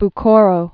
(b-kôrō, -ôrō) or Bu·kha·ra (-kärə, -ärə) also Bo·kha·ra (bō-)